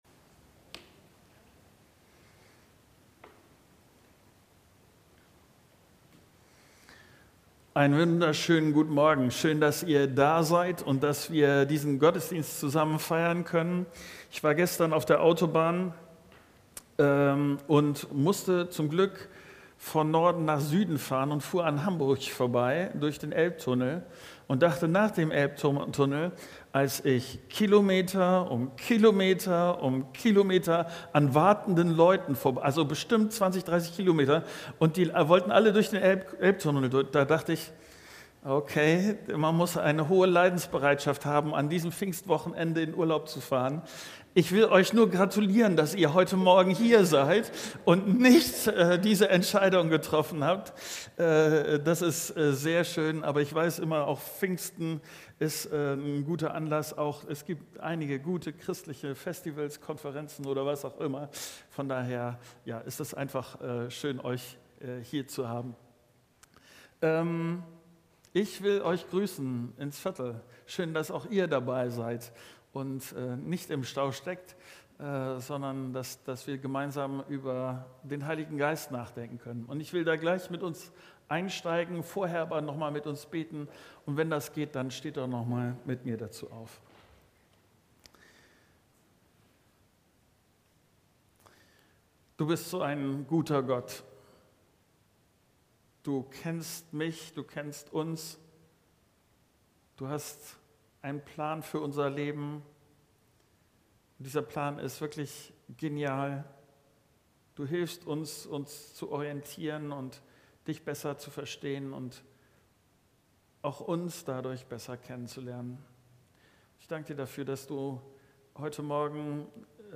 Predigten der Christus-Gemeinde | Audio-Podcast